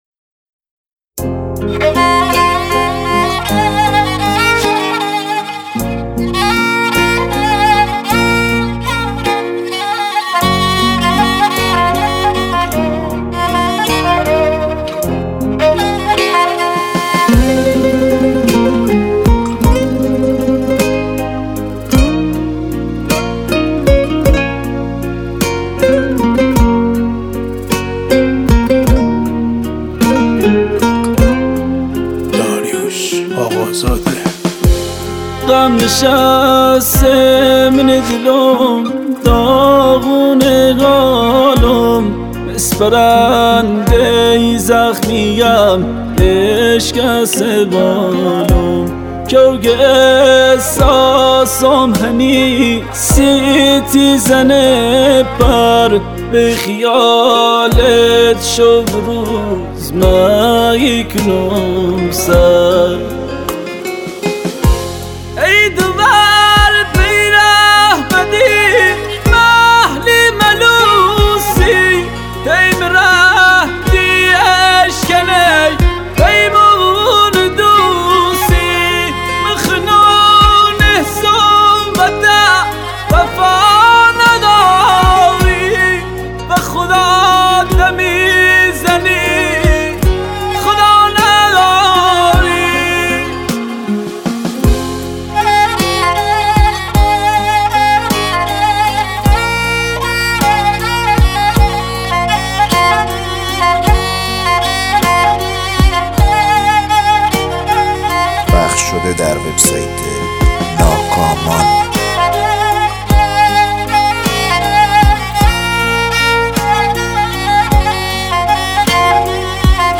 دسته بندی : دانلود آهنگ محلی تاریخ : پنجشنبه 18 دی 1399